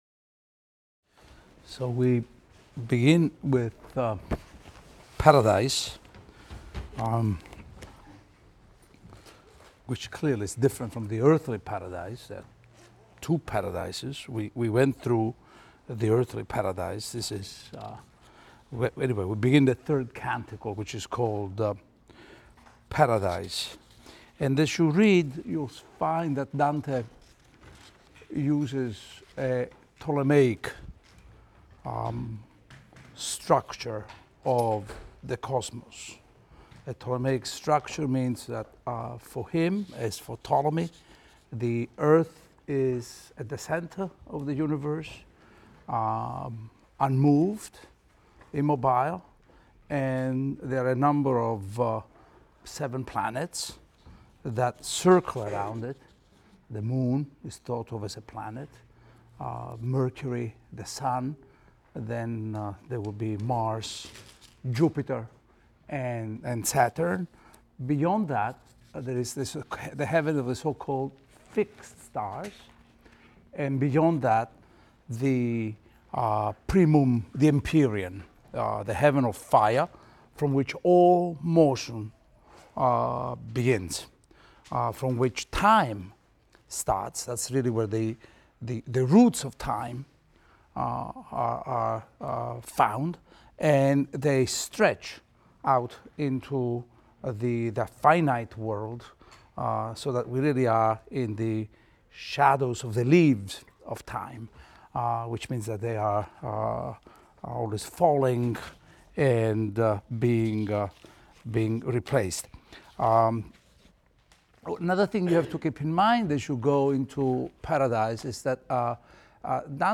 ITAL 310 - Lecture 16 - Paradise I, II | Open Yale Courses